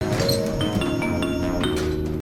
File:Elevator start.mp3